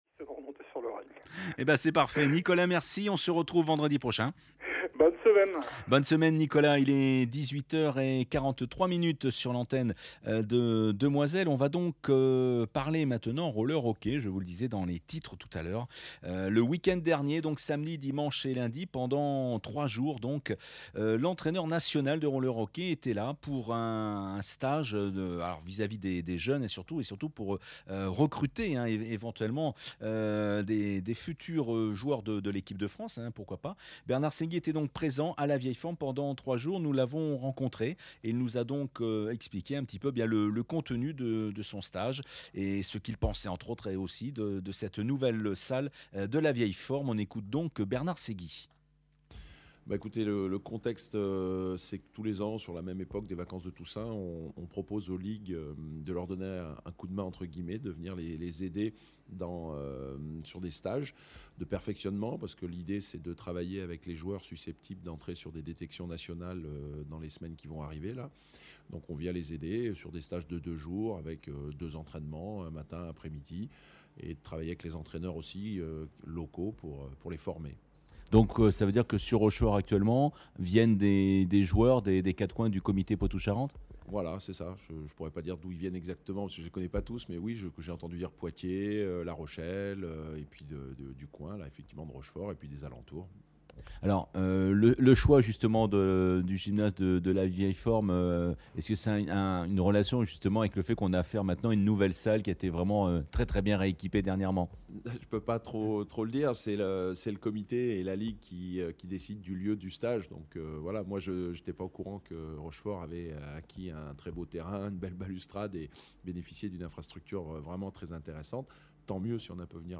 Studiosports : Interview